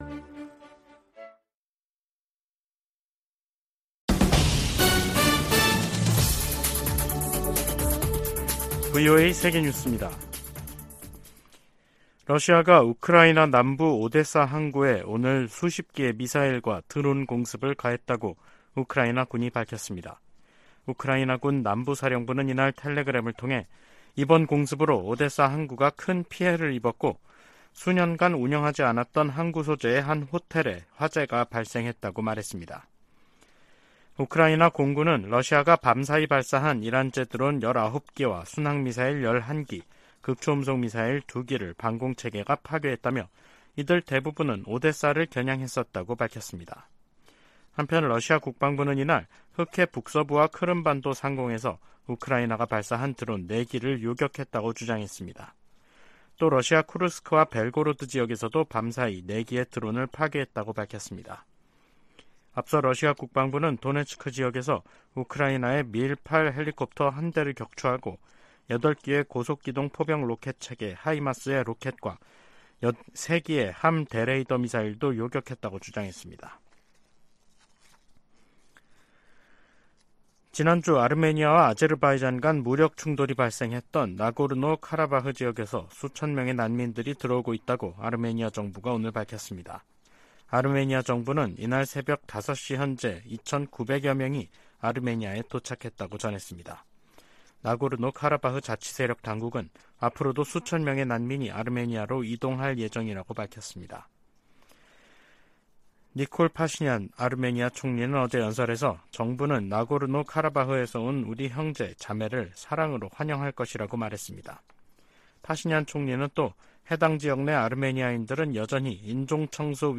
세계 뉴스와 함께 미국의 모든 것을 소개하는 '생방송 여기는 워싱턴입니다', 2023년 9월 25일 저녁 방송입니다. '지구촌 오늘'에서는 아제르바이잔이 아르메니아와의 영토 분쟁지 '나고르노-카라바흐'를 사실상 점령하면서 아르메니아 주민 탈출이 이어지는 소식 전해드리고, '아메리카 나우'에서는 조 바이든 대통령이 파업 중인 자동차노조에 대한 지지를 위해 26일 미시간주를 방문한다는 이야기 살펴보겠습니다.